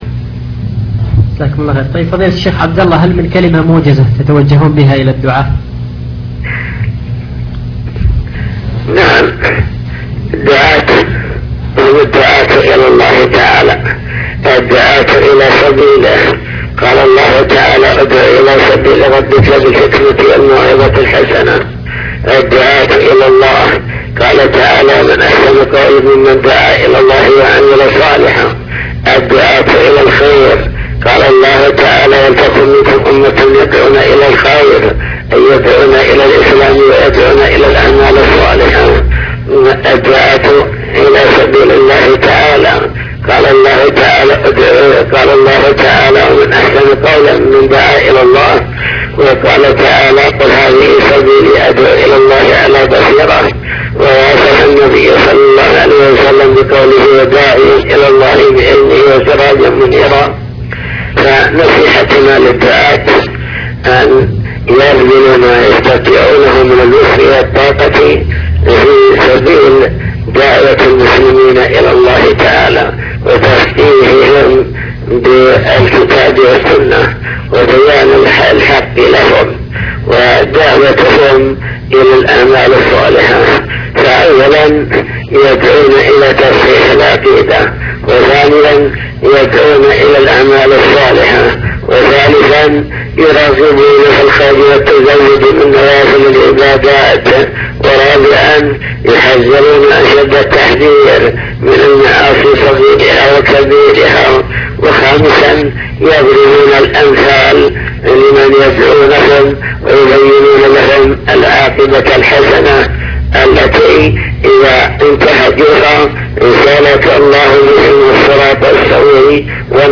حوار هاتفي